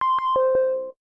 notice.wav